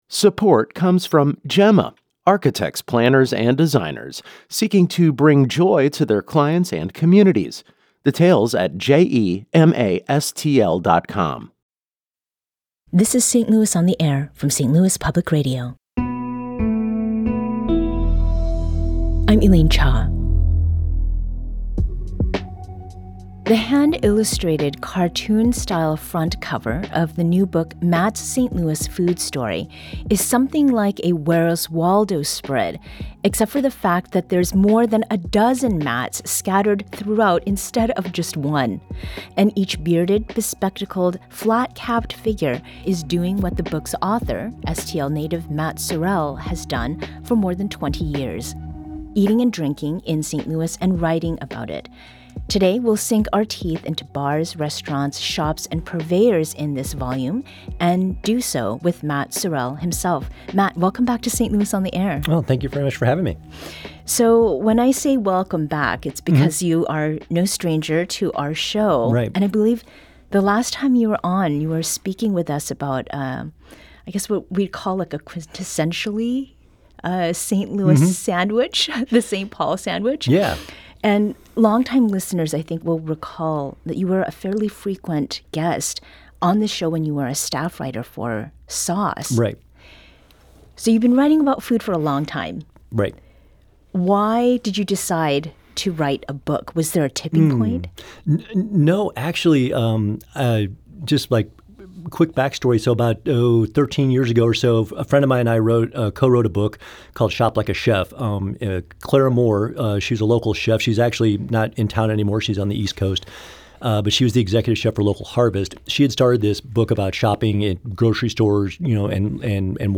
Live PIFTM Fundraiser - Part 2 50:27